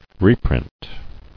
[re·print]